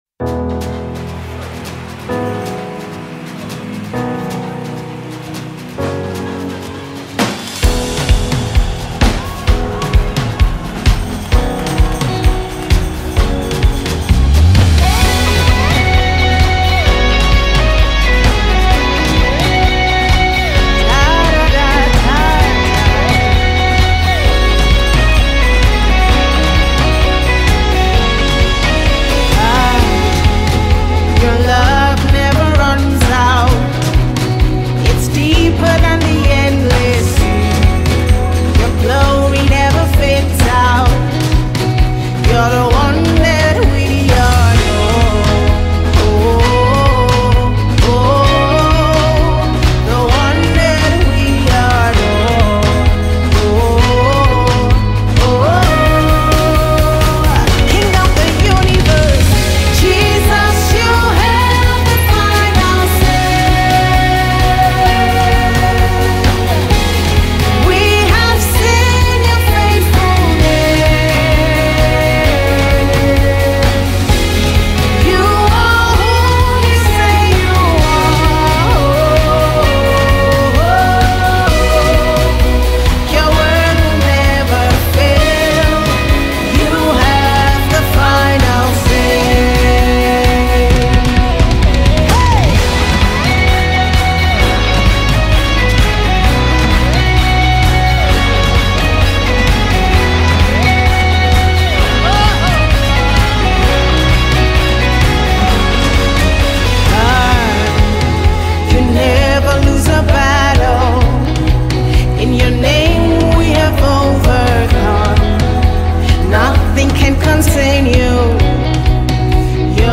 a soulful melody that lifts your spirit
Genre:Gospel